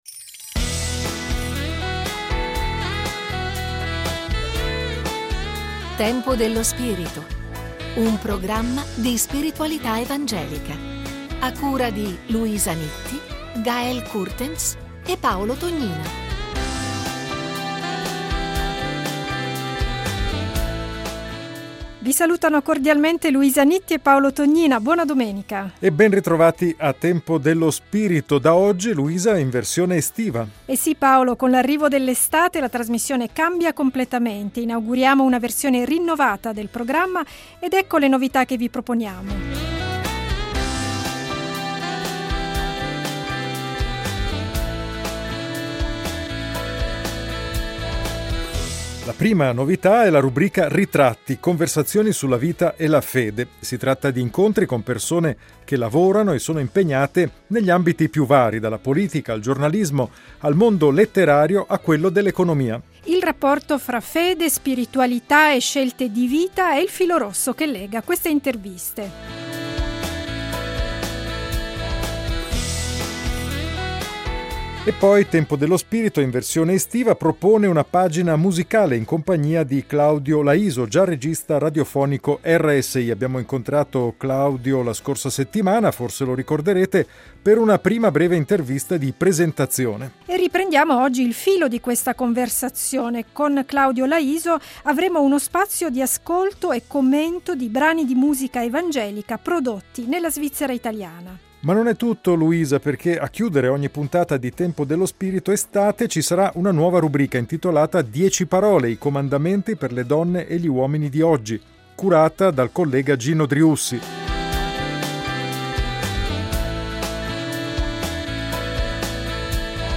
Incontri con persone che lavorano negli ambiti più vari: dalla politica, al giornalismo, al mondo letterario a quello dell’economia. Il rapporto fra fede, spiritualità e scelte di vita quotidiane è il filo rosso che lega queste interviste.